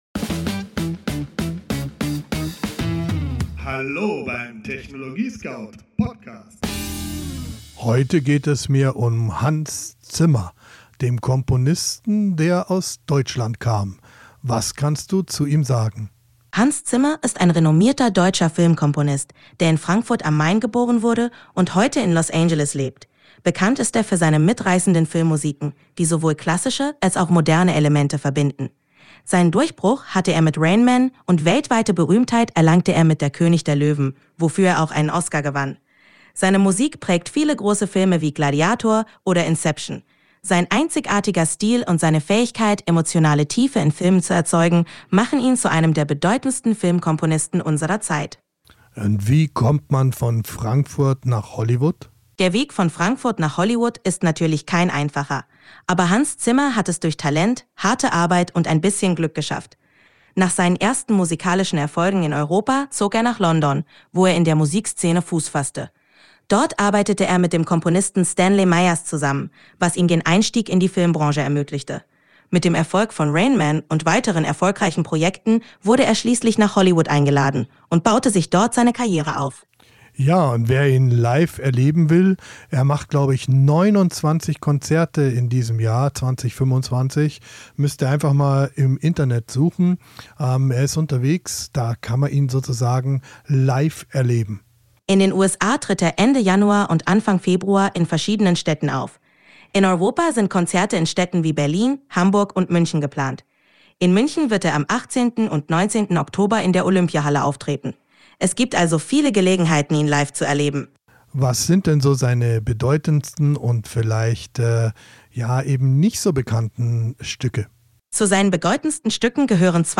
Dialog im TechnologieScout-Studio